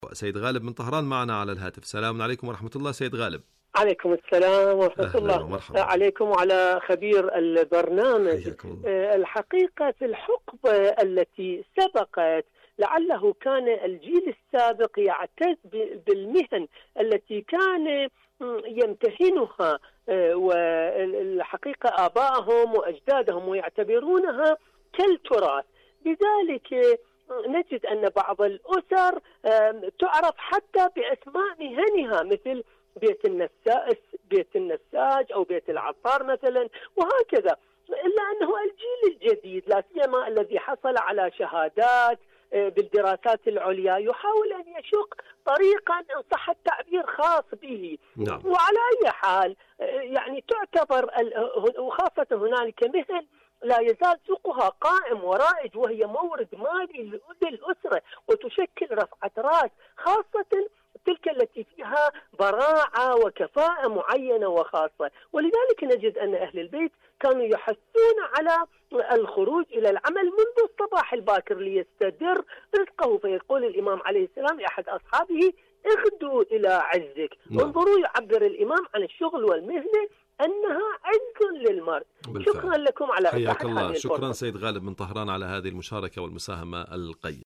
مشاركة واتساب صوتية
إذاعة طهران- معكم على الهواء